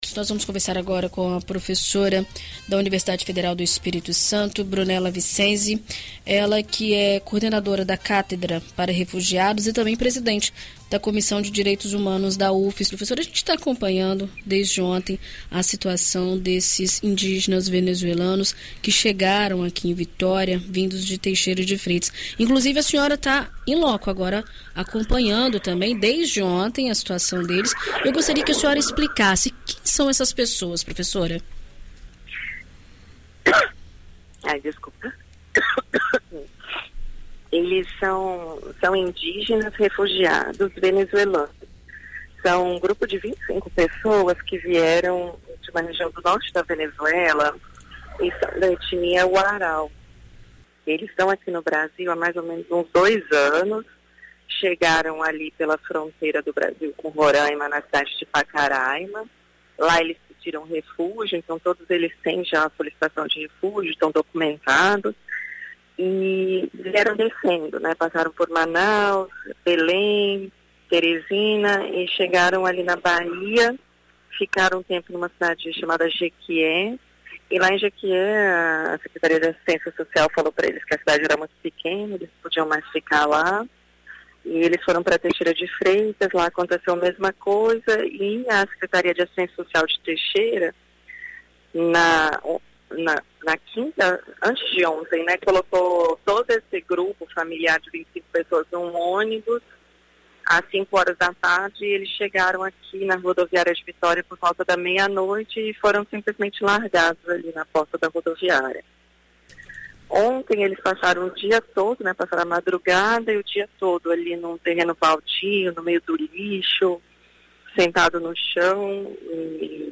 Em entrevista à BandNews FM Espírito Santo nesta quarta-feira (17)